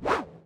ctf_ranged_throw.ogg